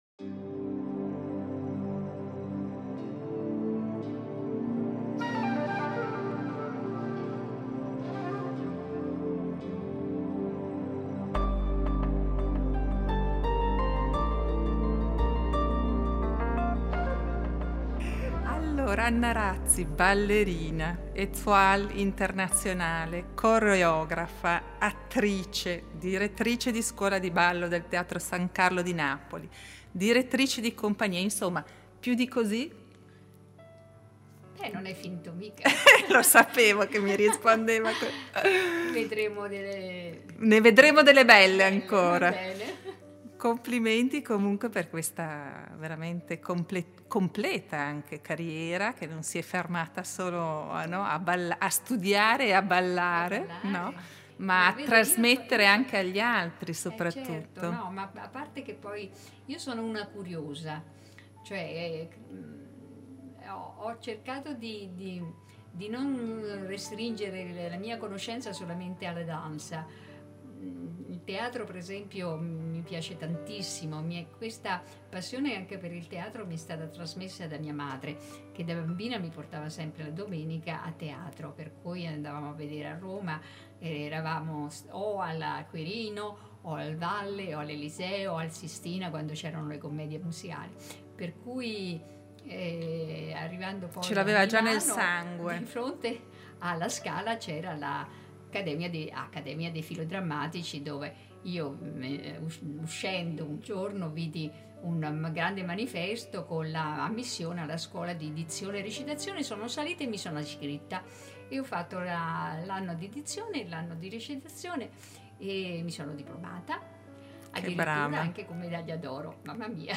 Io la incontrai una decina di anni fa per un’intervista alla radio.